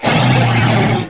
cry_not_lycanroc_dusk.aif